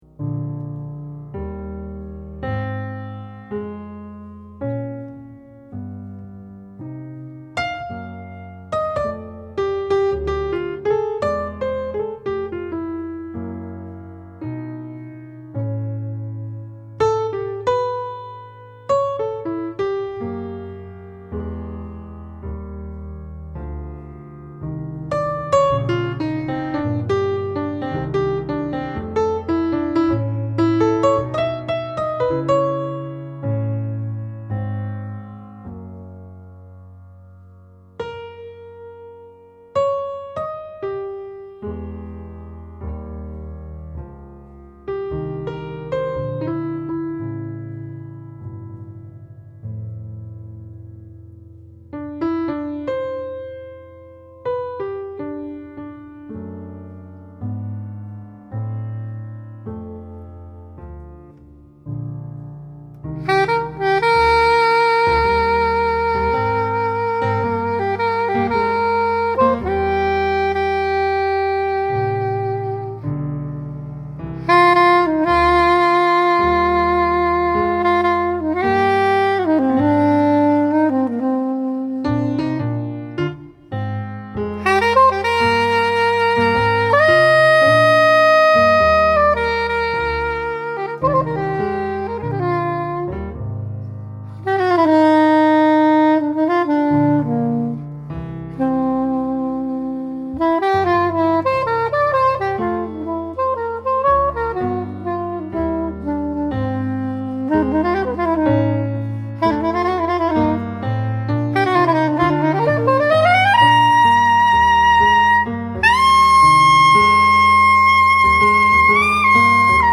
piano, drums
flute, saxophone, shakuhachi
Spontaneous improvisation- no predetermined form.